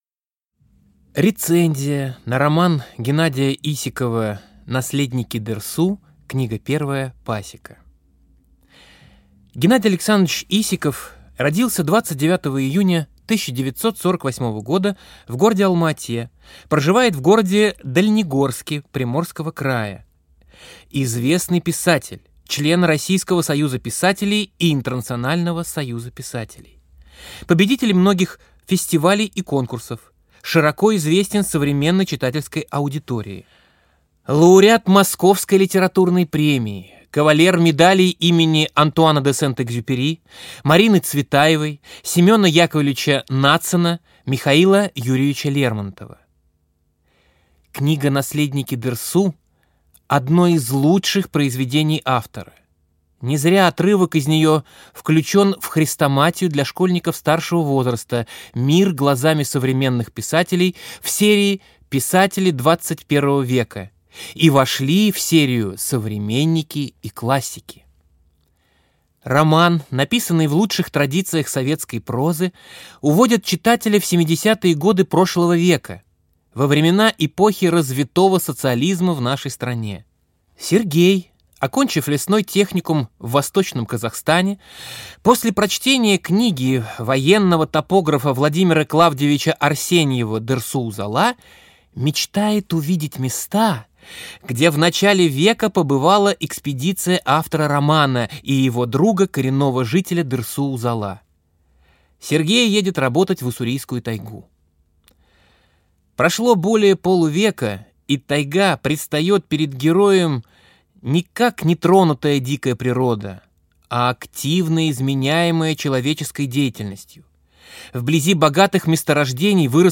Аудиокнига Наследники Дерсу. Книга 1. Пасека | Библиотека аудиокниг